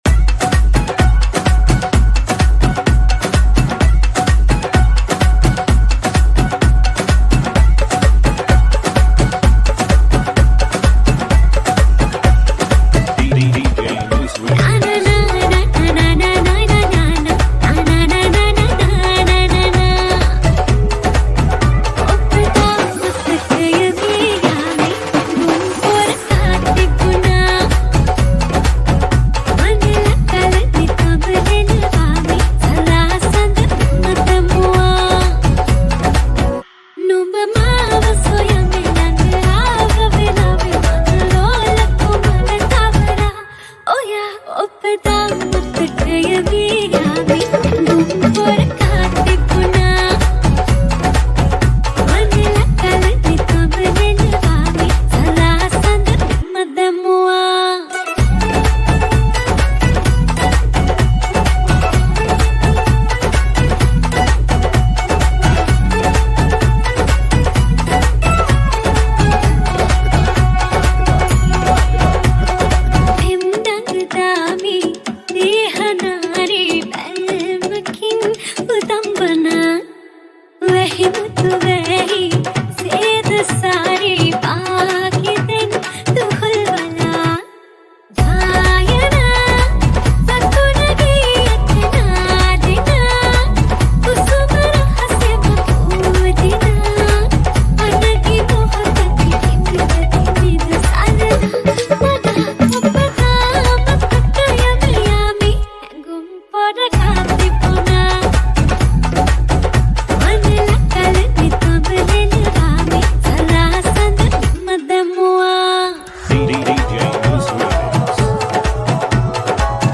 Panjabi House Mix